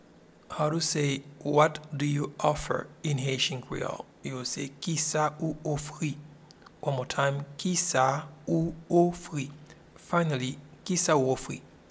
Pronunciation and Transcript:
What-do-you-offer-in-Haitian-Creole-Kisa-ou-ofri.mp3